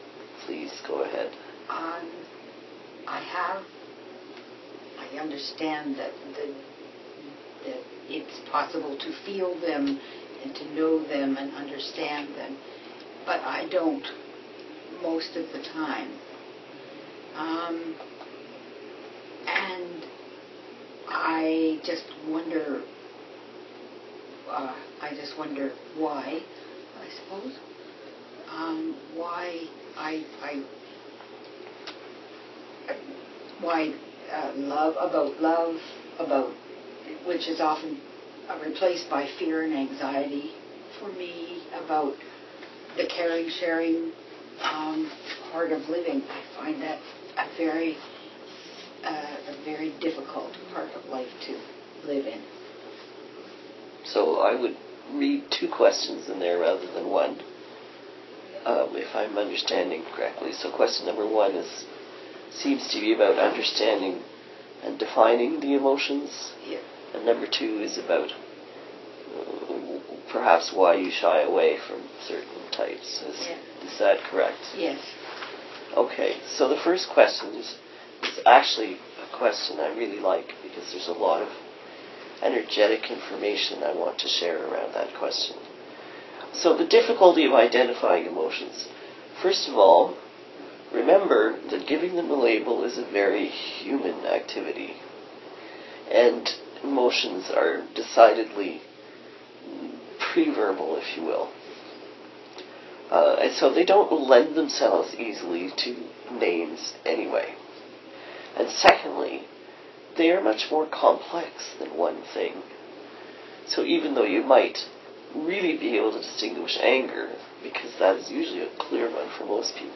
This is a male guide who came to me specifically for the purpose of channeling.  Here he is answering a question from a guest about emotions.
Sample-Guest-Question-Response-Re-Emotions-4-Feb-2017-Circle.m4a